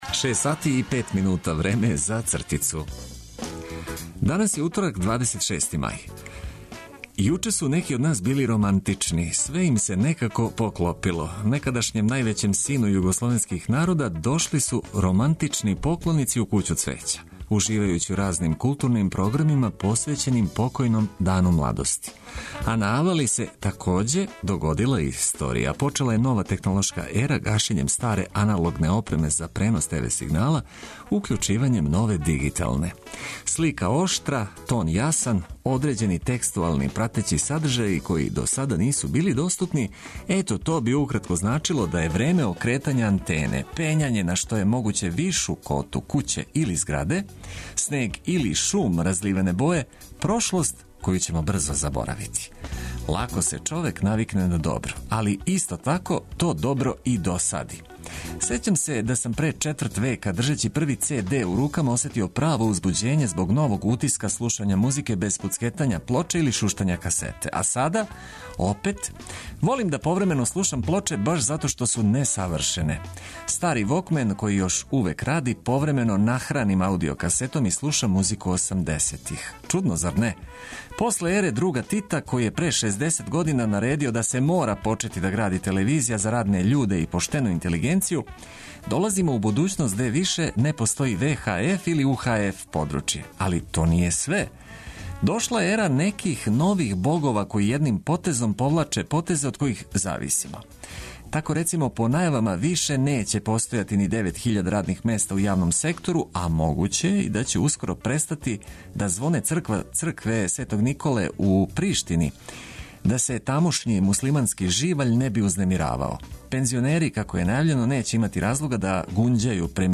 Започнимо дан уз осмех и ведру музику, добро расположени упркос свему.